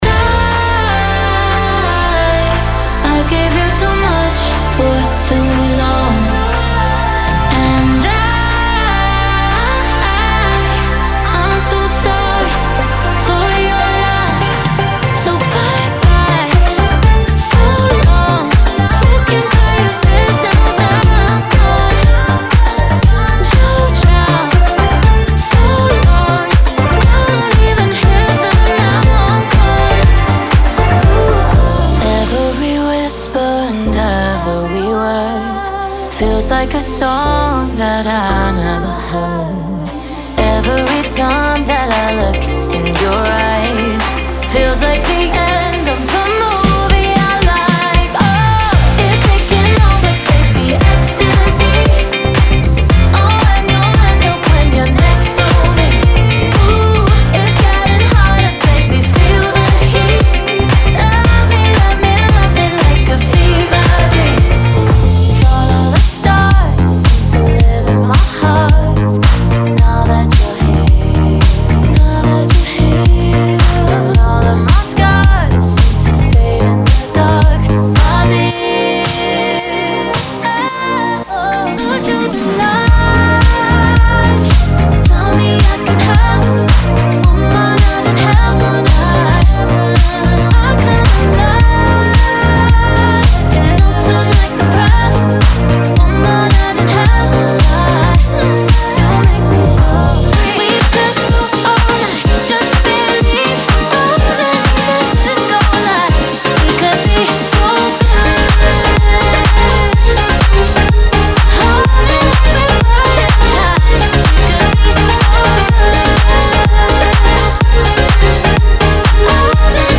Genre:Disco
本パックの核となるのは、380以上に及ぶ女性ボーカルのループとワンショットです。
リッチなハーモニーから耳に残るフックまで幅広く収録されており、トップライン、チョップ、加工用テクスチャとして理想的です。
デモサウンドはコチラ↓